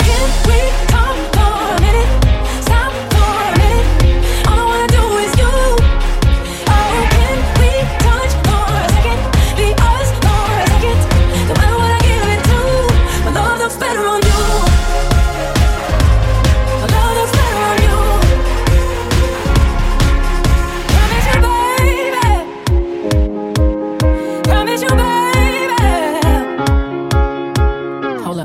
Genere: pop,house, deep, club, remix